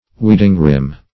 Search Result for " weeding-rhim" : The Collaborative International Dictionary of English v.0.48: Weeding-rhim \Weed"ing-rhim`\, n. [Cf. Prov.